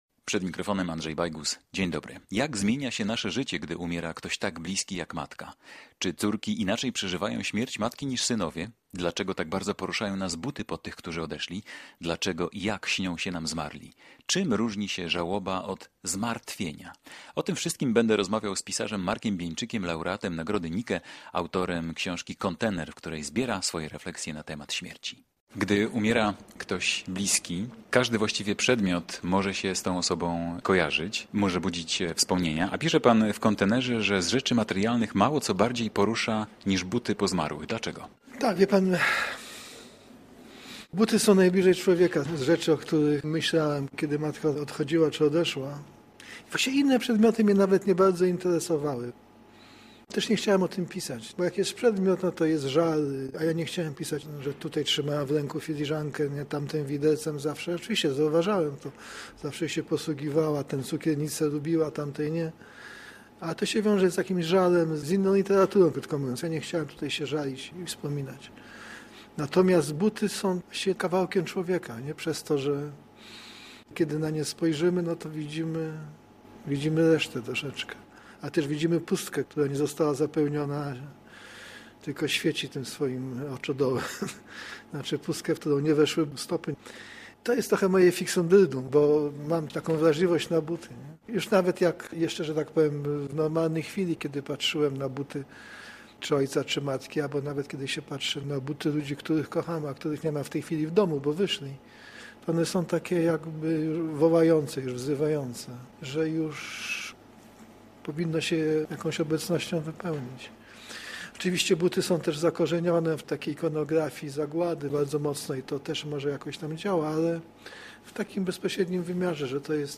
Marek Bieńczyk - pisarz, laureat nagrody Nike